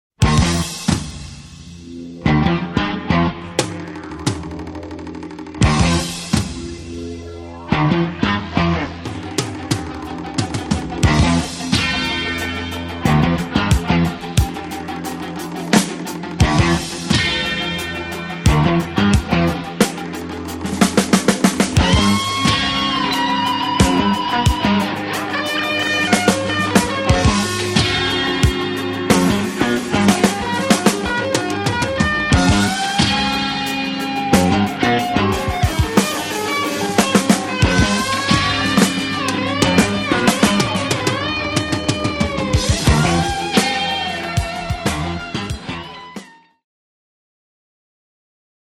Ziemlich Kult, teils ziemlich Police-artig,